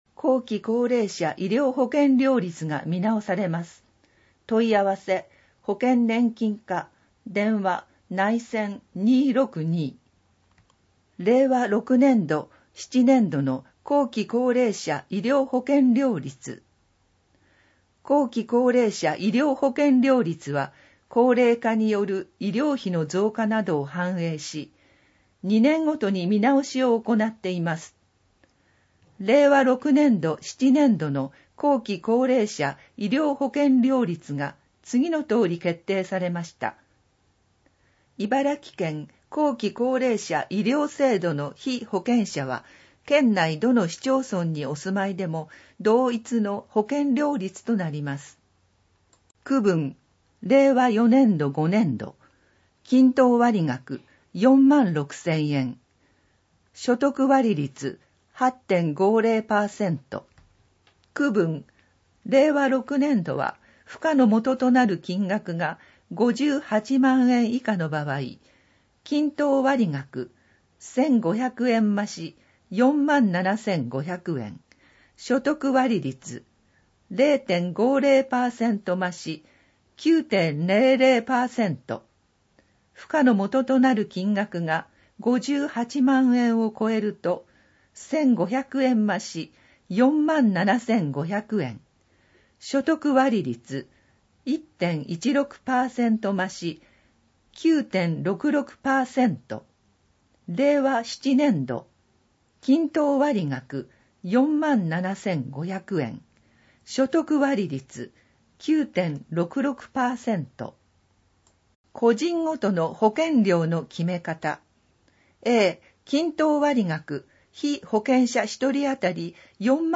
音訳データダウンロード
※MP3データは「龍ケ崎朗読の会」のご協力により作成しています。